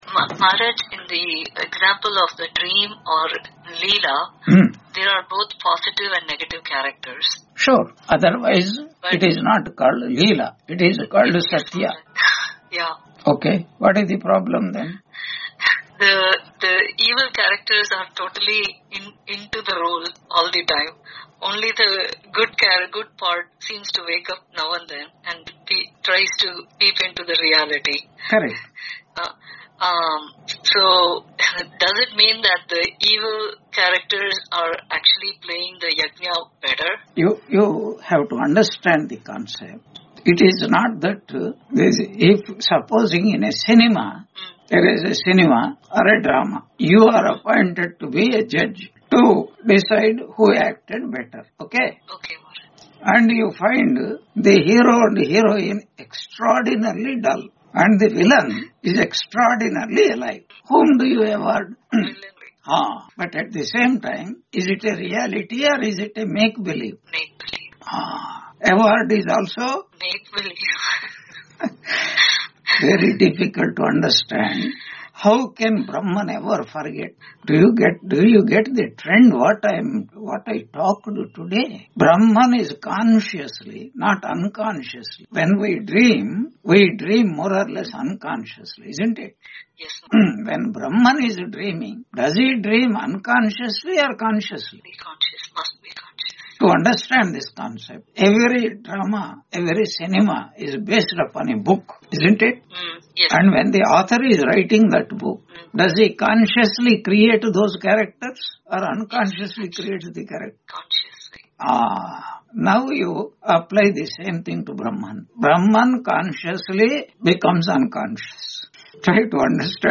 Chandogya Upanishad 5.3 Introduction Lecture 159 on 29 November 2025 Q&A - Wiki Vedanta